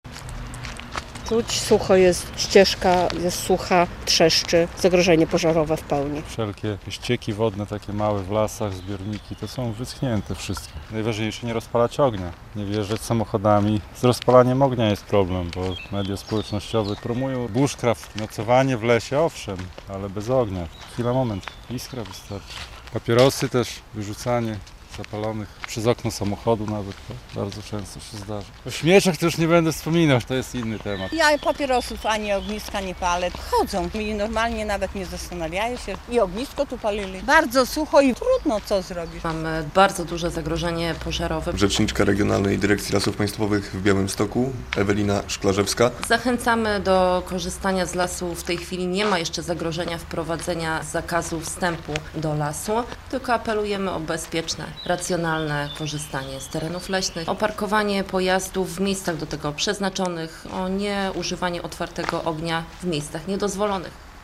Sucho w lasach - relacja